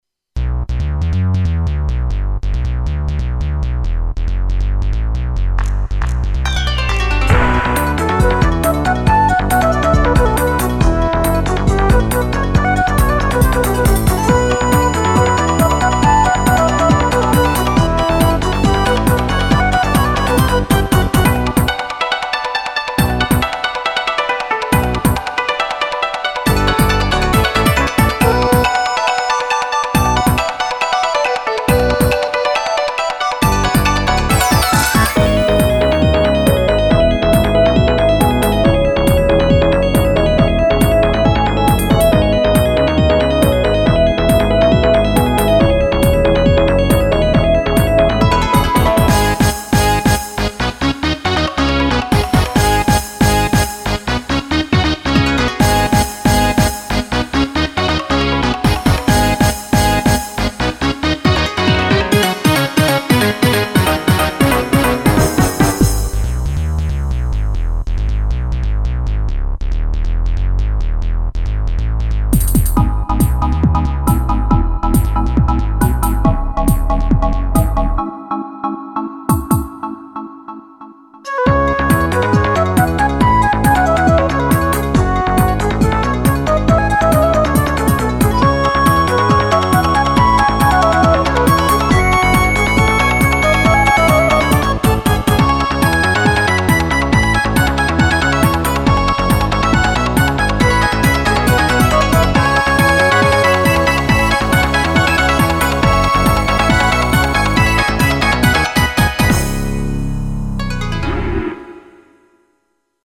この頃は音源の裏バンクを使うのが楽しくて、いろいろふだん使わない音を捻じ込んでたりしました。